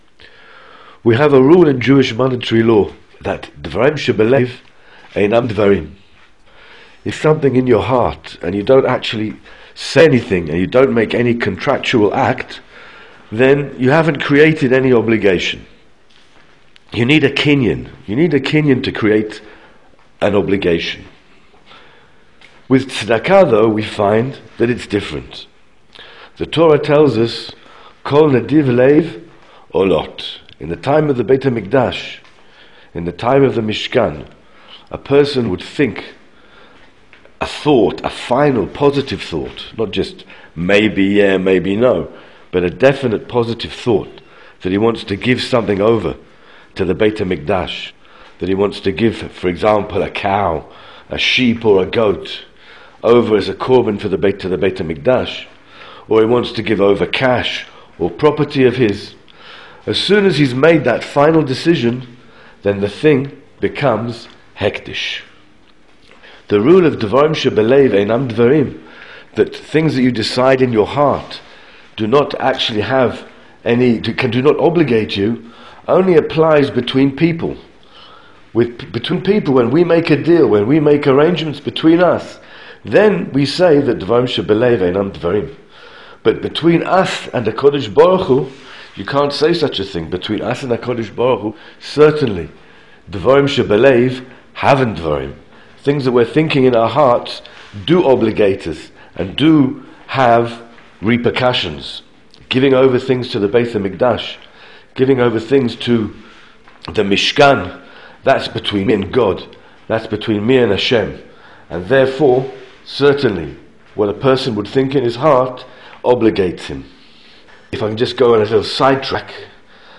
An Audio Shiur on non-verbal commitments to Tzeddaka